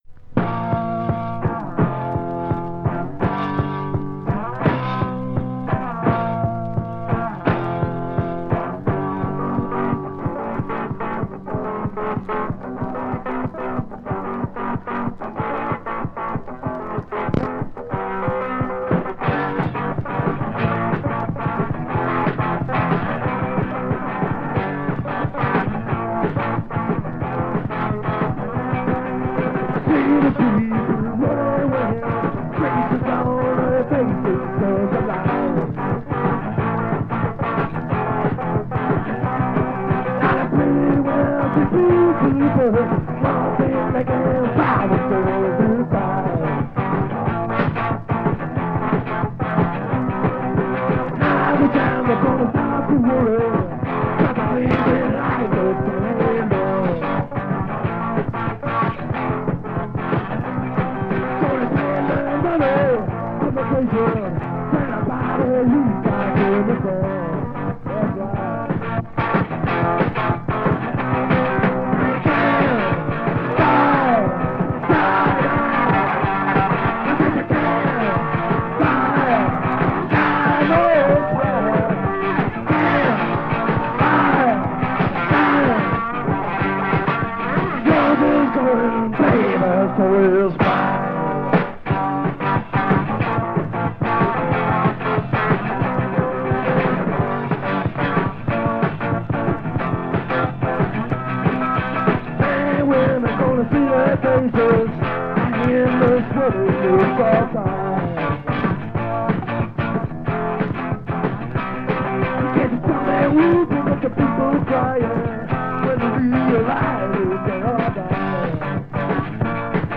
lieferte die Gruppe klassischen Punkrock ab.